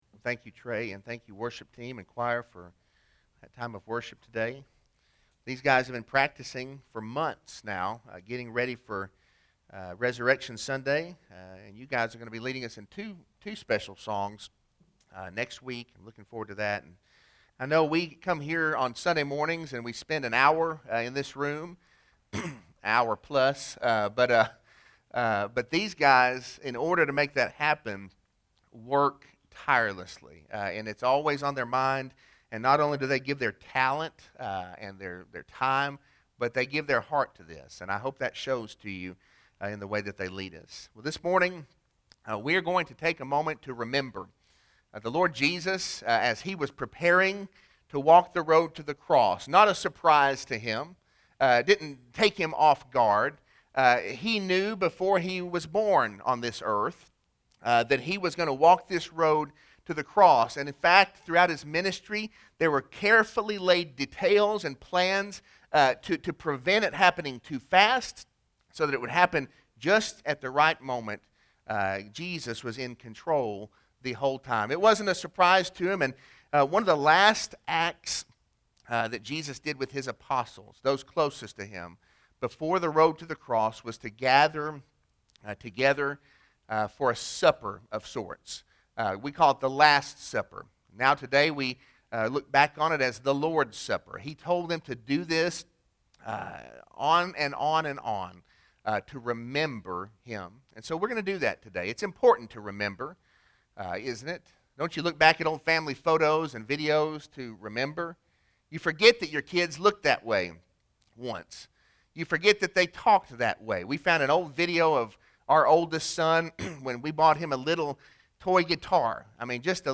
Sermon-3-28-21.mp3